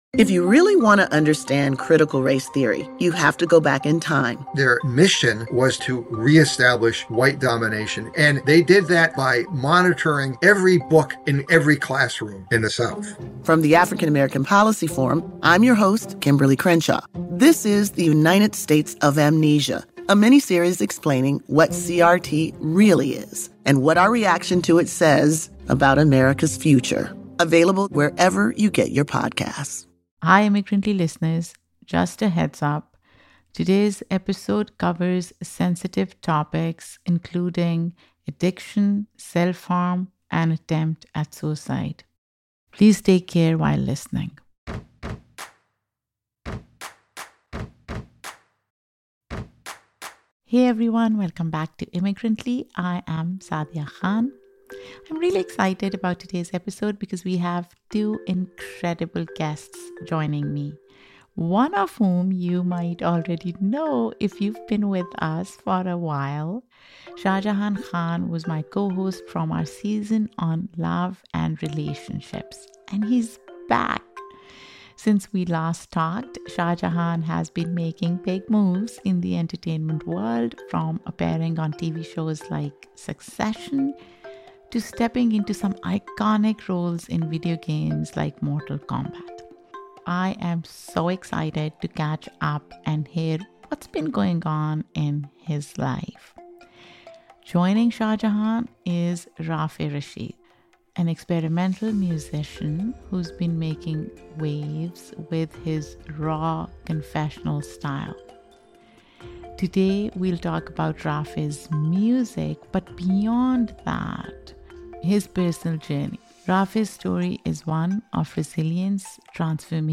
This is a conversation about the art of healing and inspiring. Immigrantly is a weekly podcast that celebrates the extraordinariness of immigrant life.